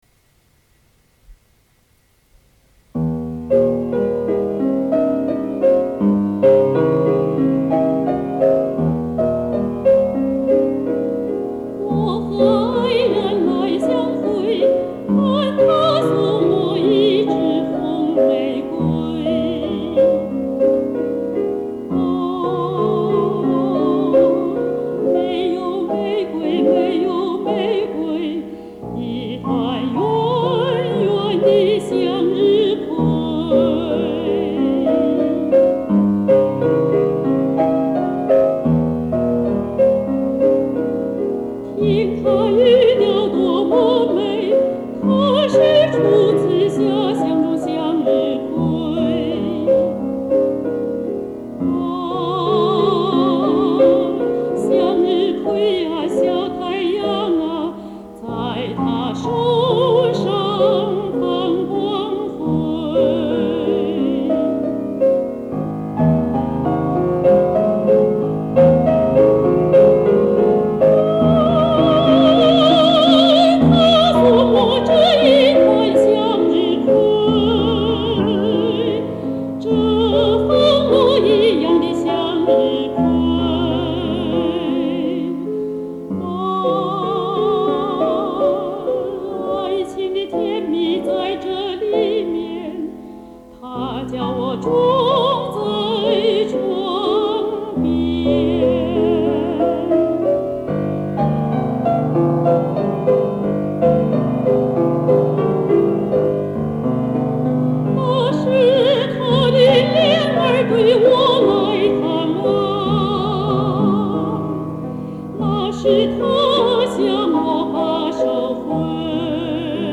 这首歌曲是当下学院女高音必然要唱的一首艺术歌曲。
这首歌曲或许转调频繁，并不符合旋律上口的要求，所以一般老百姓哼哼它还是有点困难的，但曲趣值得品味，揭示内心情感的波澜有极为曼妙的感觉。